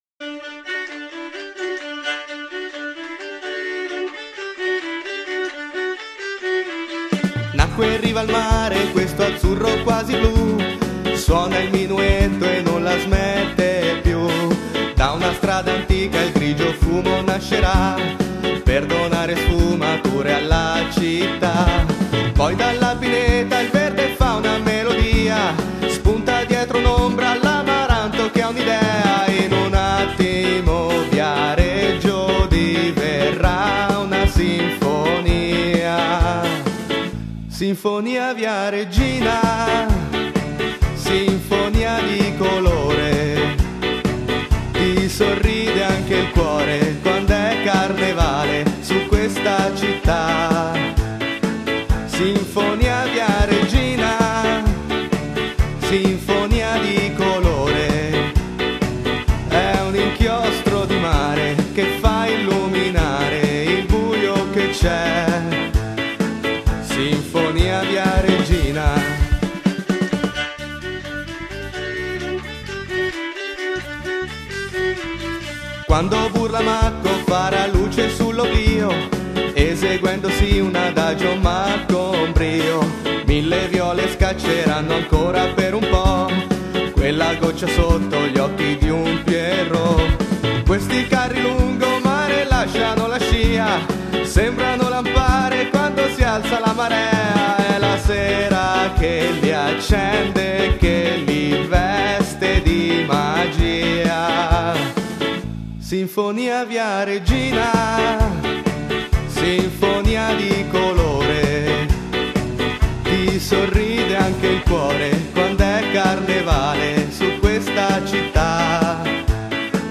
bella canzoncina...peccato lui sia stonato